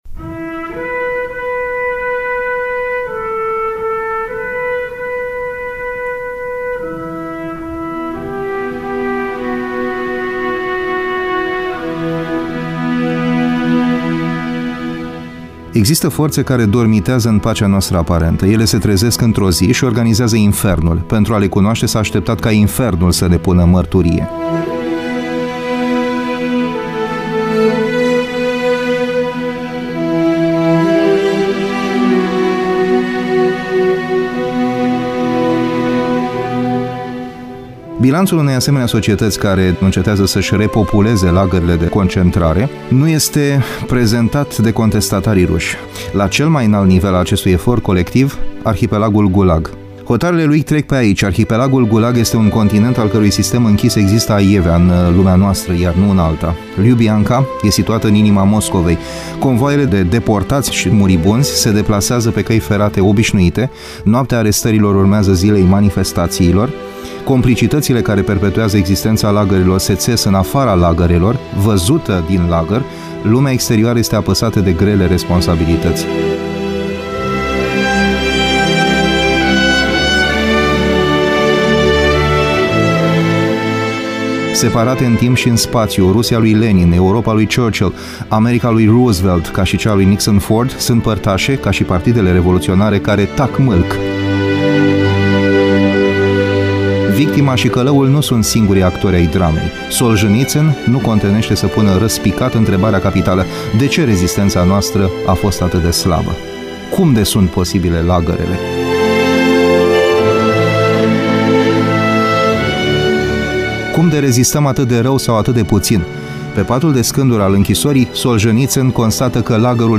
comentariu